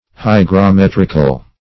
Search Result for " hygrometrical" : The Collaborative International Dictionary of English v.0.48: Hygrometric \Hy`gro*met"ric\, Hygrometrical \Hy`gro*met"ric*al\, a. [Cf. F. hygrom['e]trique.] 1.
hygrometrical.mp3